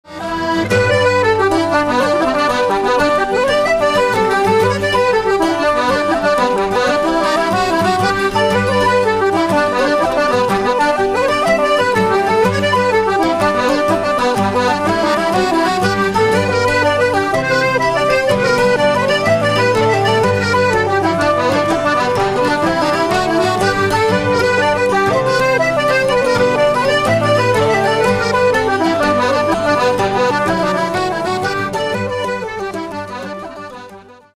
Toronto based Irish accordion player
jig